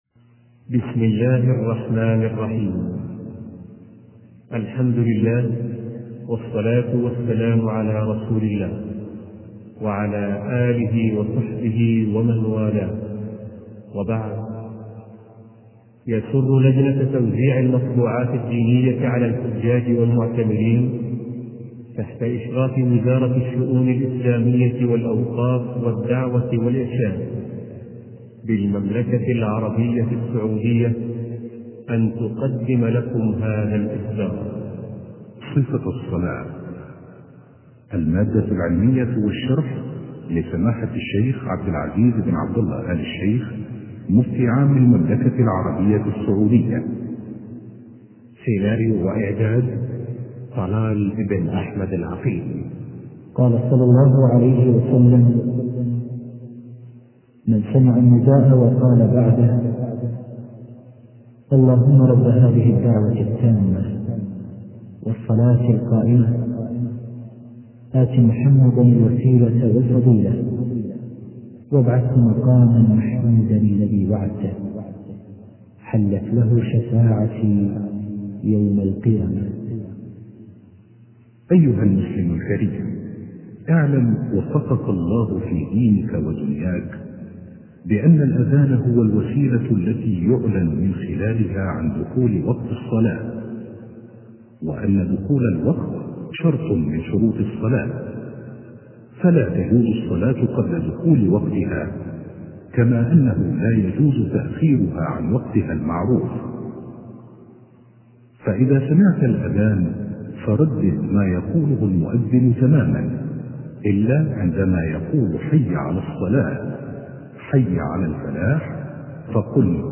شبكة المعرفة الإسلامية | الدروس | صفة الصلاة |عبد العزيز آل الشيخ